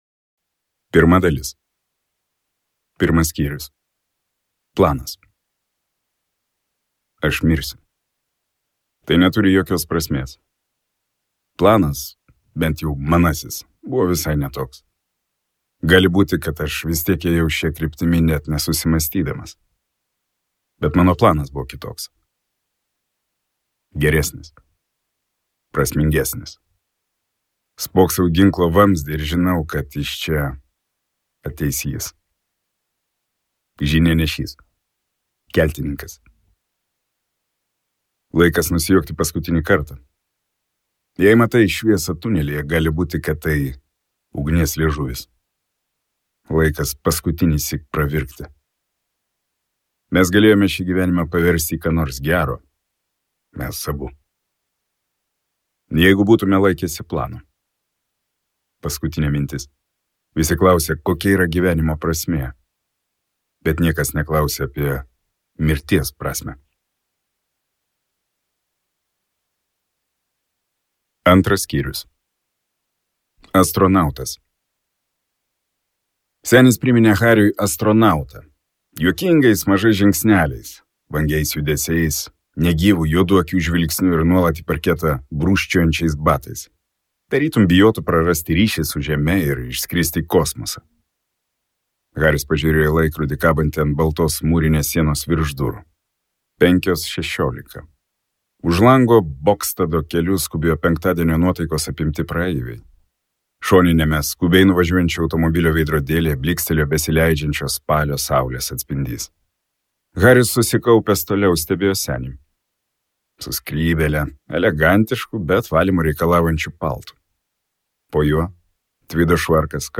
Nemezidė | Audioknygos | baltos lankos